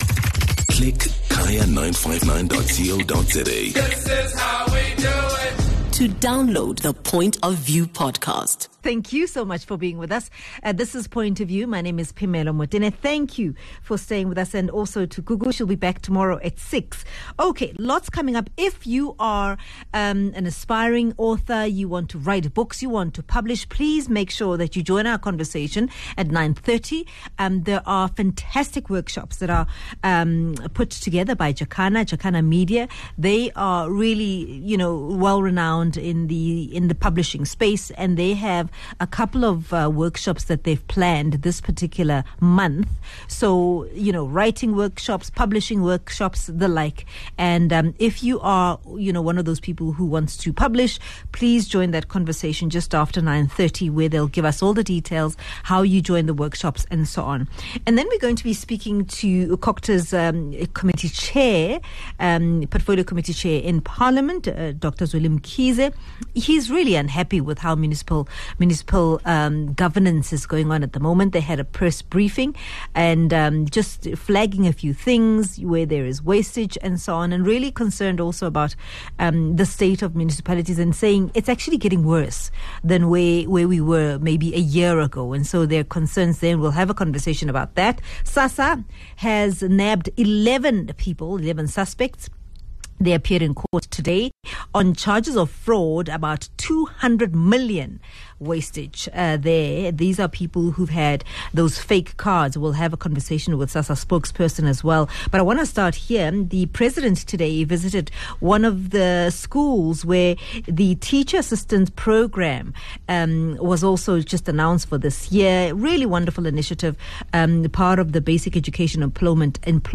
President Cyril Ramaphosa says “Teacher assistants are building the future of South Africa,” Ramaphosa was speaking during a visit to Sefako Makgatho Primary School in Saulsville. He was there to celebrate a major milestone for the Basic Education Employment Initiative (BEEI), which has now helped over two million young South Africans find opportunities and gain valuable work experience.